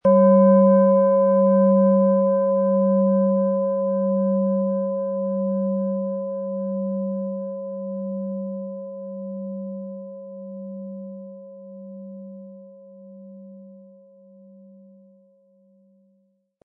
OM Ton
Planetenschale®
Viel Freude haben Sie mit einer OM-Ton, wenn Sie sie sanft mit dem kostenlosen Klöppel anspielen.
MaterialBronze